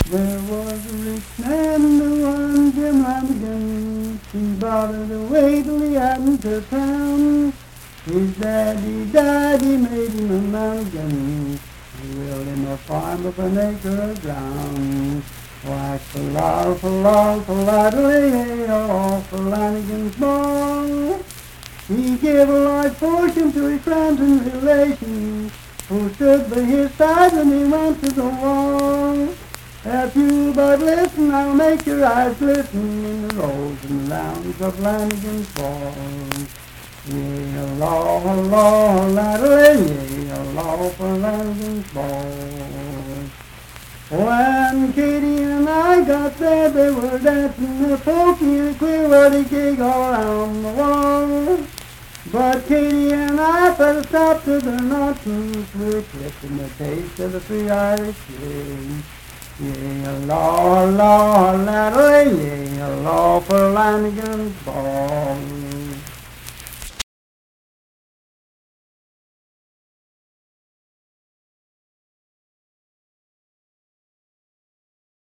Unaccompanied vocal performance
Ethnic Songs
Voice (sung)
Randolph County (W. Va.)